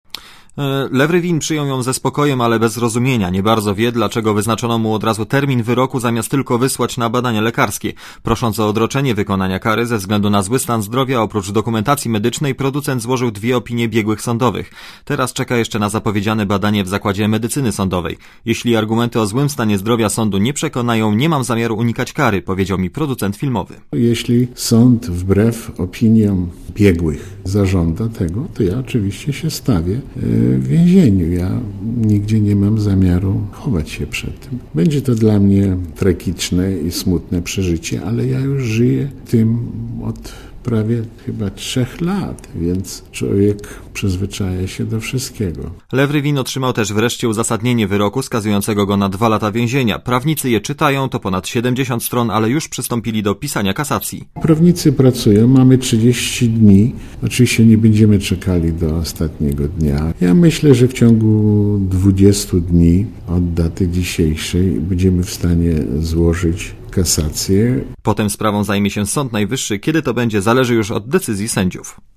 Nie mam zamiaru chować się przed decyzją sądu. Jeśli mimo złego stanu zdrowia sąd nakaże mi odbycie kary, to stawię się w więzieniu - powiedział reporterowi Radia ZET Lew Rywin. Zgodnie z decyzją sądu, Rywin ma się zgłosić 18 kwietnia w zakładzie karnym przy ulicy Rakowieckiej w Warszawie.